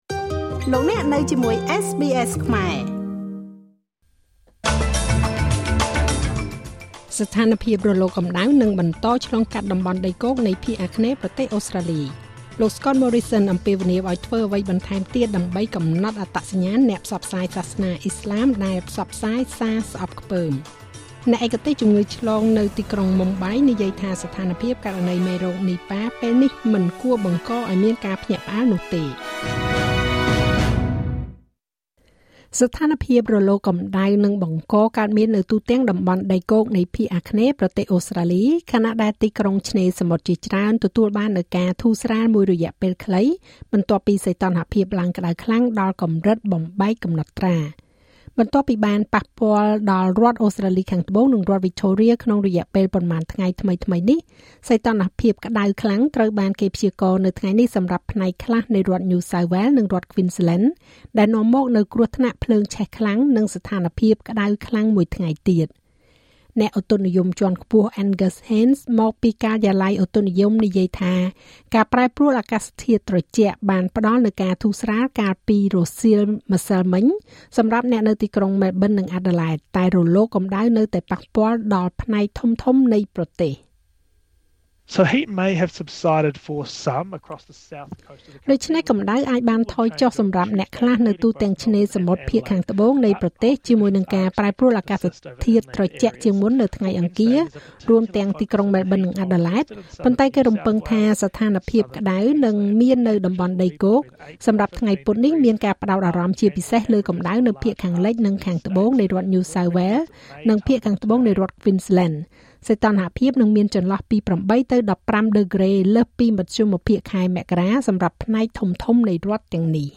នាទីព័ត៌មានរបស់SBSខ្មែរ សម្រាប់ថ្ងៃពុធ ទី២៨ ខែមករា ឆ្នាំ២០២៦